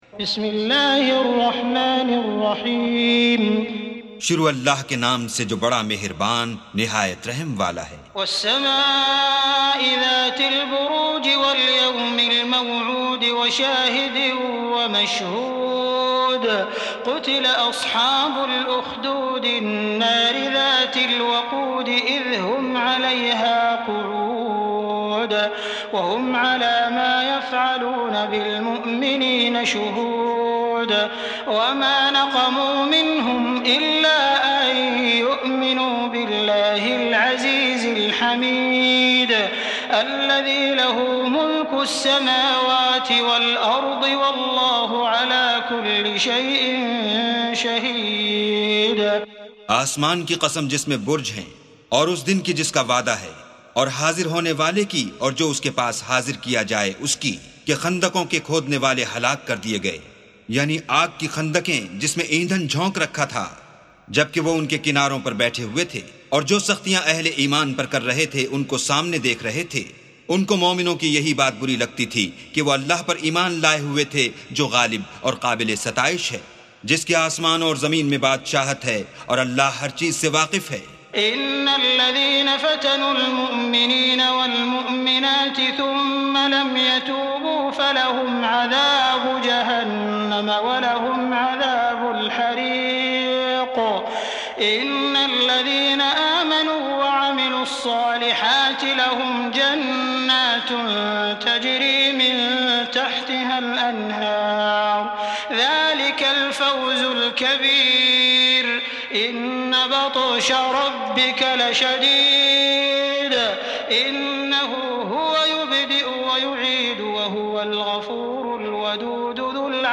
سُورَةُ البُرُوجِ بصوت الشيخ السديس والشريم مترجم إلى الاردو